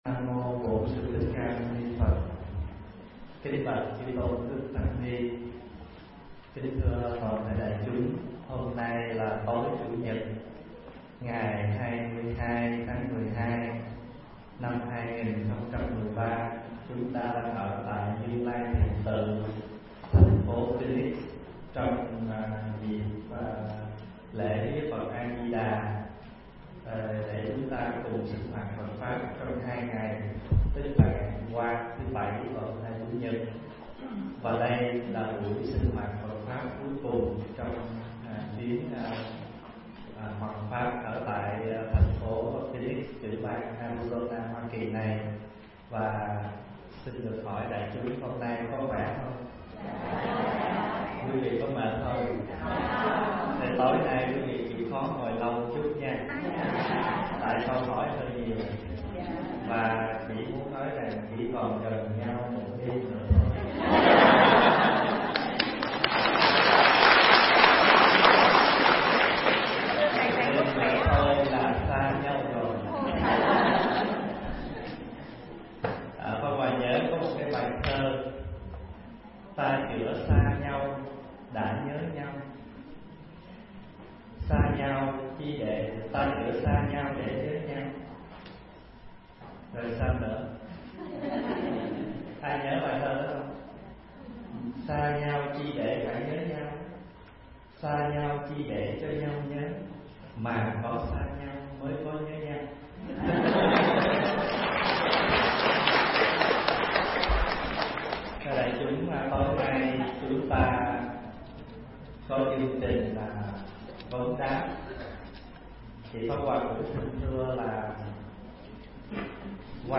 thuyết giảng tại Phoenix, Arizona